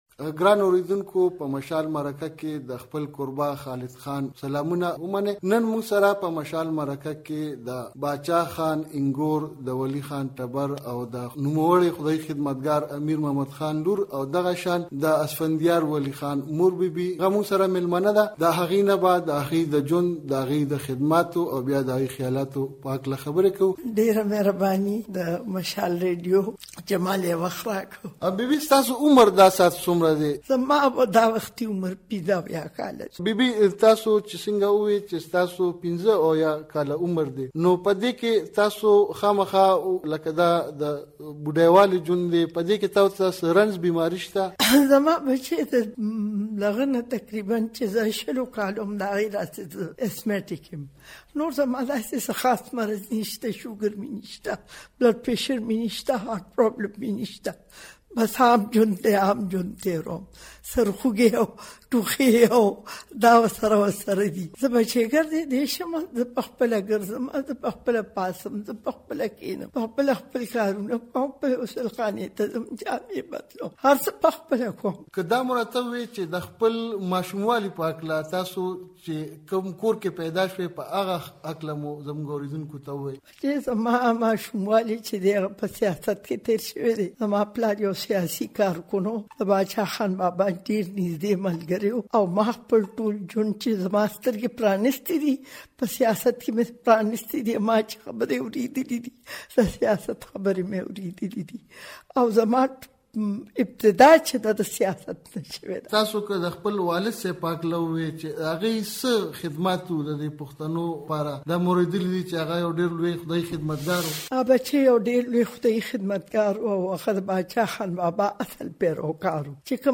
میرمن نسیم ولي خان په مشال مرکه کې